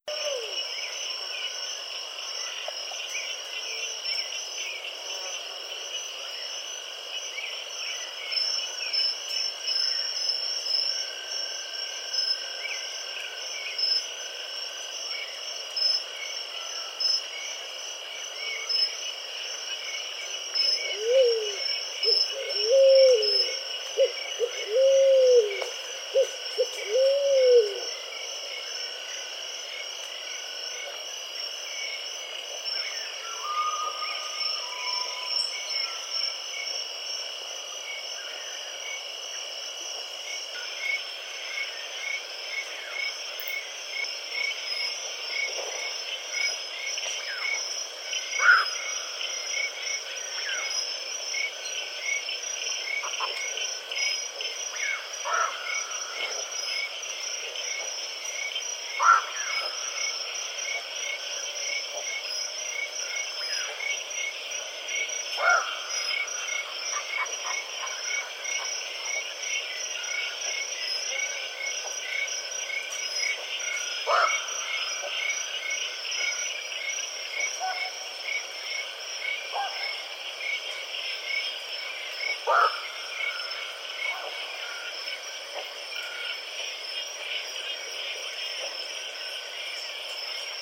• creamy-bellied-thrush and woodpecker.wav
creamy-bellied-thrush_and_woodpecker_CKW.wav